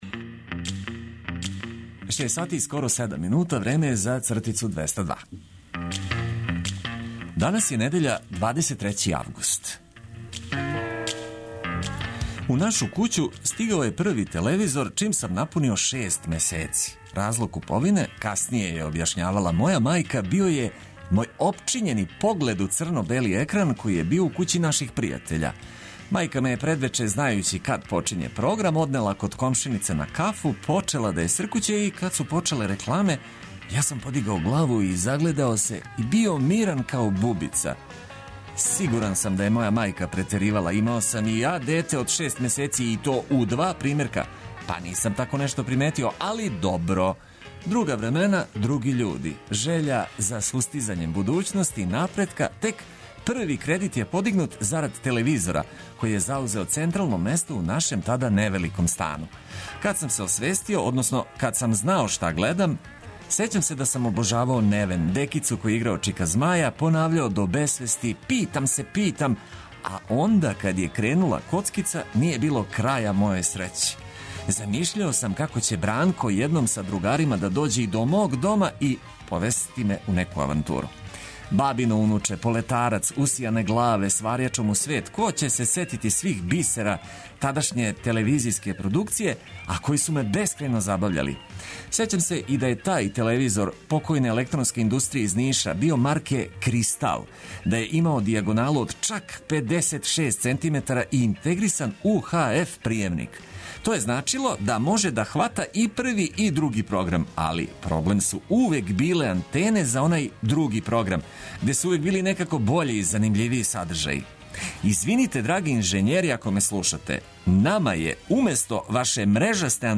Нека бар недеља буде опуштенија уз много музике и сасвим мало приче.